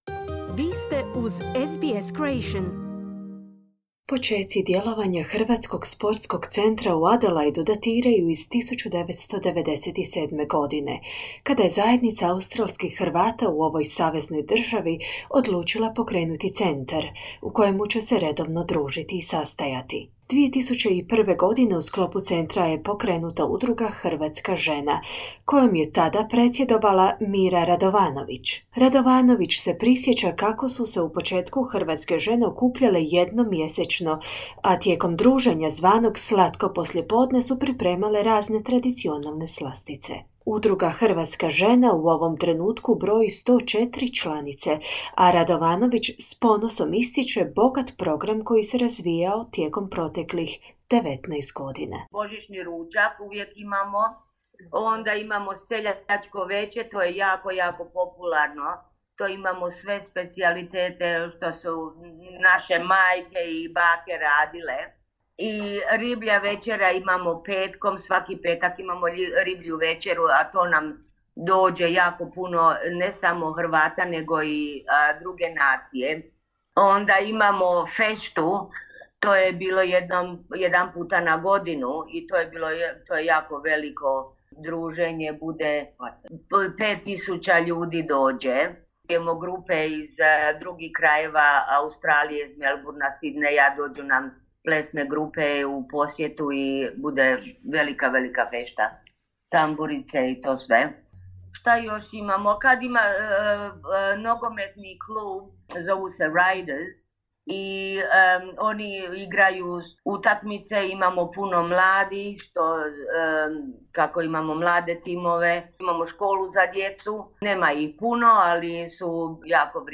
predstavnice udruge Hrvatska žena koja djeluje u sklopu Hrvatskog sportskog centra u Adelaideu